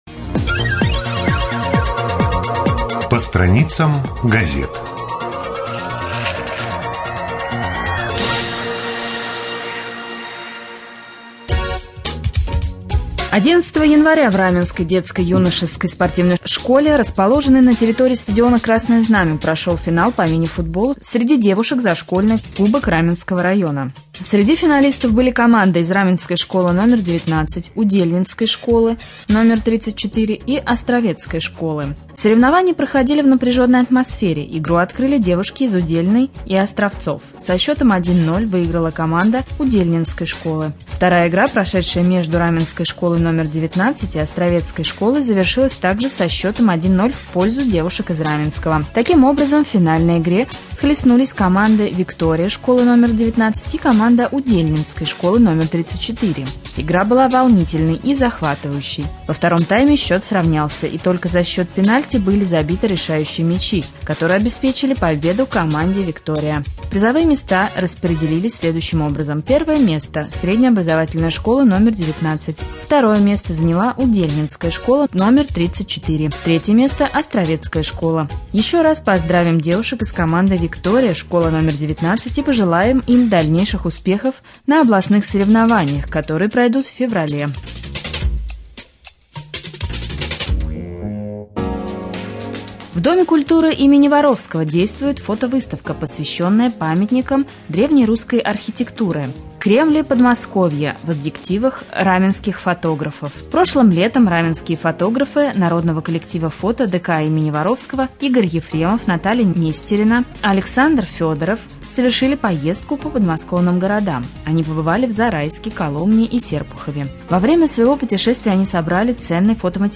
22.01.2014г. в эфире раменского радио - РамМедиа - Раменский муниципальный округ - Раменское